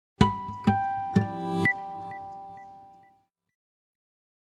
Samsung Galaxy Bildirim Sesleri - Dijital Eşik
Harmonics
harmonics.mp3